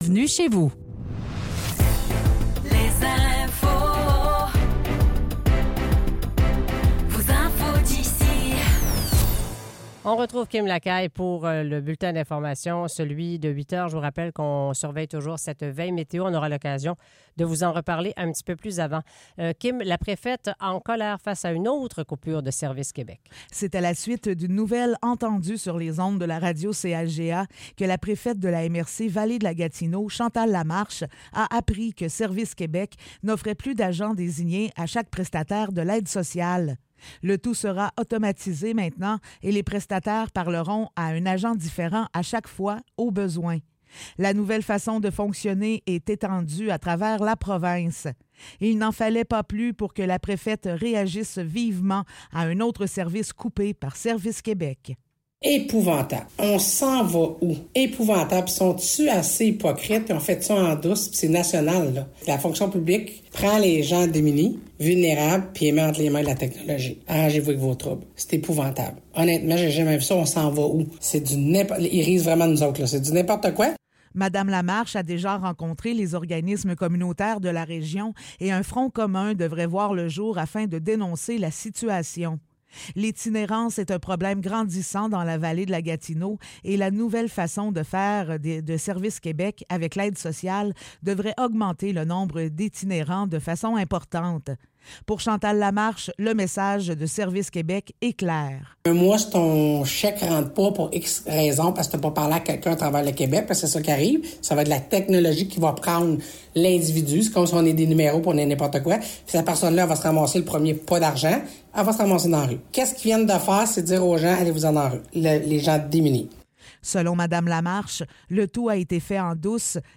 Nouvelles locales - 13 juin 2024 - 8 h